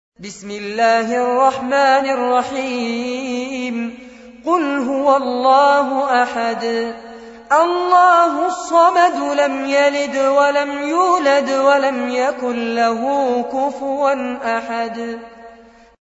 أحد أشهر قراء القرآن الكريم في العالم الإسلامي، يتميز بجمال صوته وقوة نفسه وإتقانه للمقامات الموسيقية في التلاوة.
تلاوات المصحف المجود